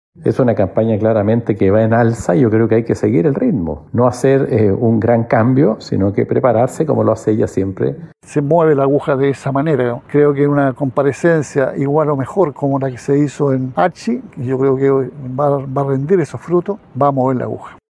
En ese sentido, el diputado Jaime Mulet (FRVS) afirmó que la abanderada debe “mantener el tono” del debate Archi.
En paralelo, el líder del Partido Comunista, Lautaro Carmona, enfatizó que el debate Anatel moverá la aguja a favor de Jara.